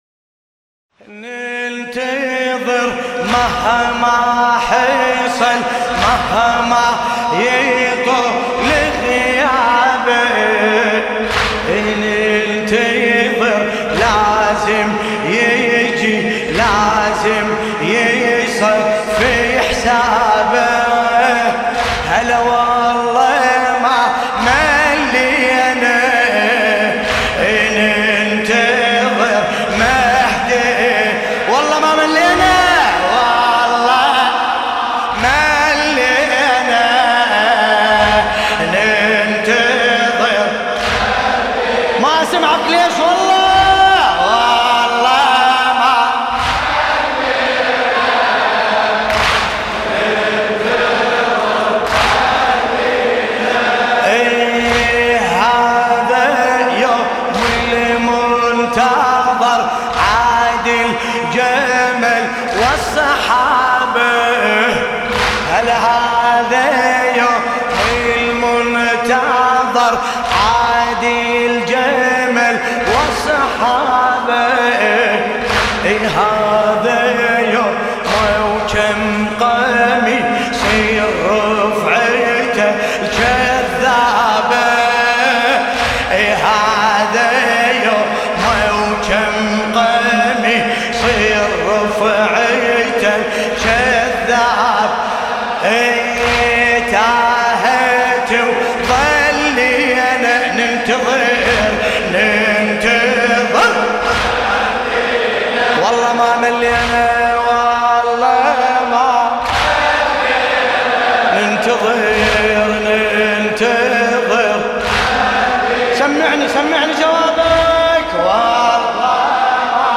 ليالي الفاطمية حسينية الرسول الأعظم - ننتظر مهما حصل